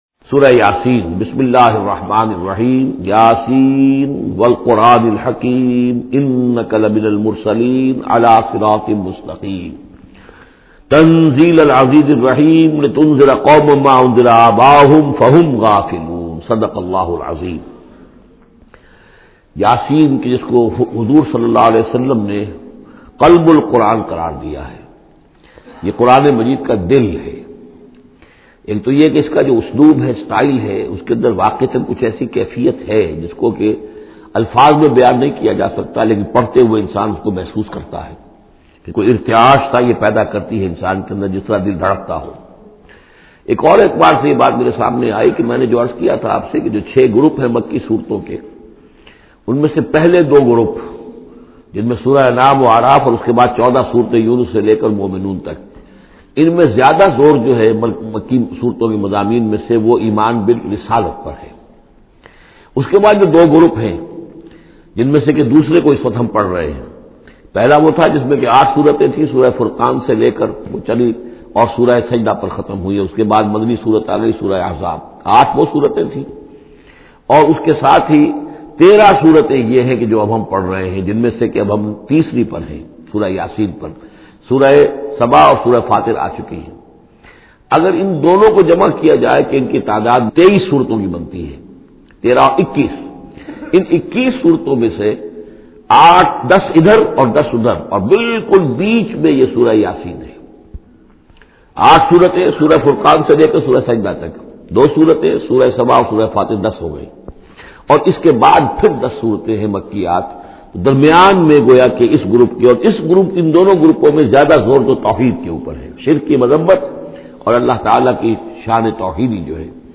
Surah Yaseen Urdu Tafseer by Dr Israr Ahmed
Listen this audio tafseer in the voice of Dr Israr Ahmed.